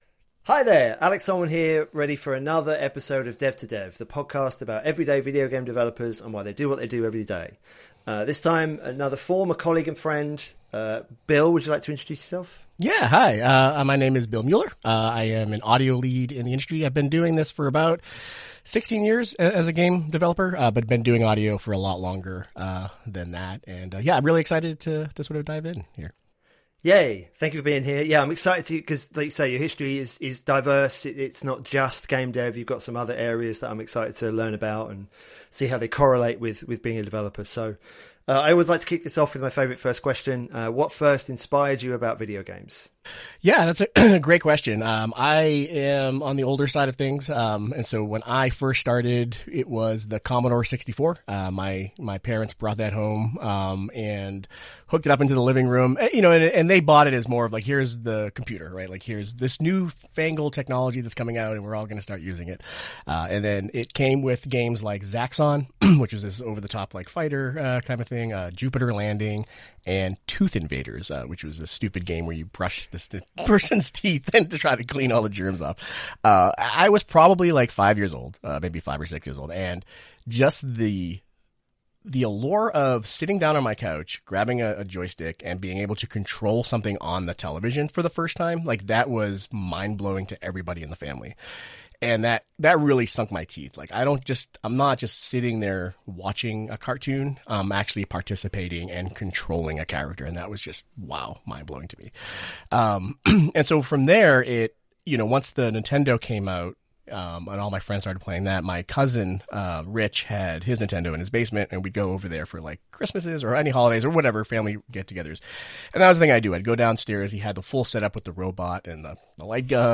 We get a look at the game from which it came before hearing about the development proper. Dev Game Club looks at classic video games and plays through them over several episodes, providing commentary.